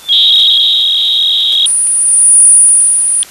Начало » Записи » Радиоcигналы на опознание и анализ
Сигнал FSK-2, Shift=500Hz, Br=300Bd.